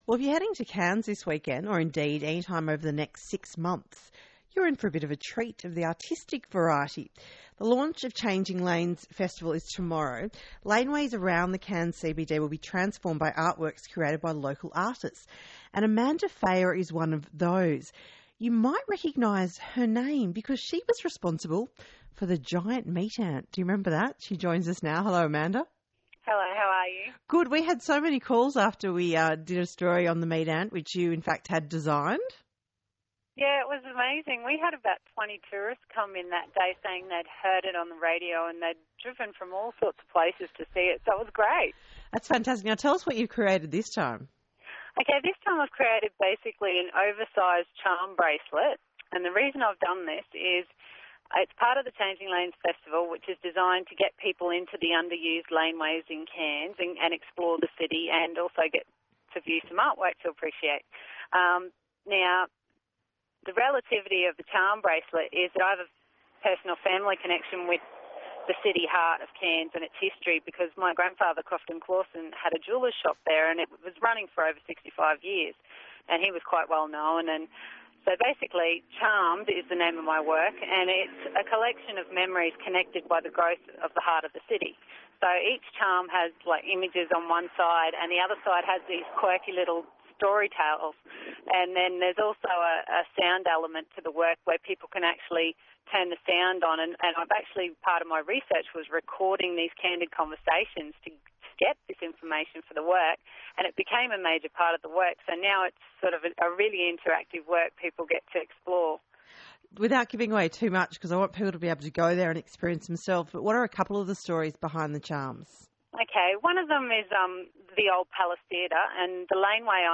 Below is an ABC Tropical North Interview with the “Afternoons” show regarding the piece, aired on 6/5/2011.